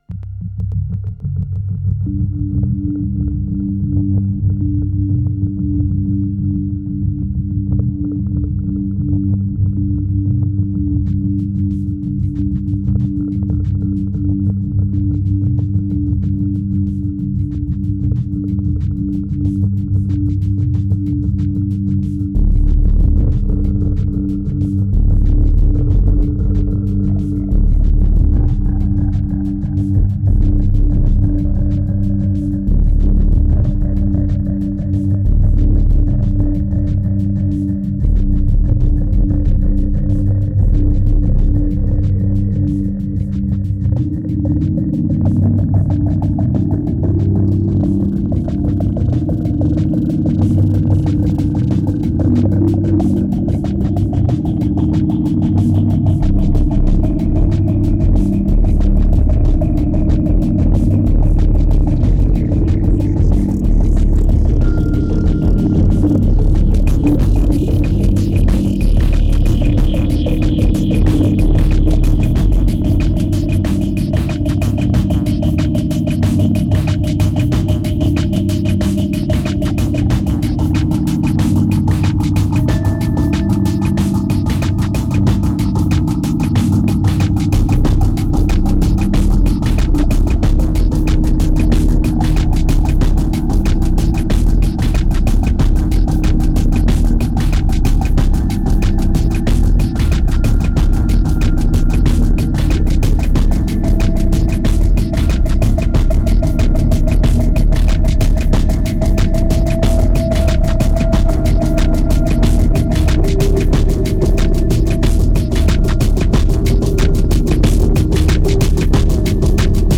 2539📈 - -9%🤔 - 93BPM🔊 - 2011-01-23📅 - -309🌟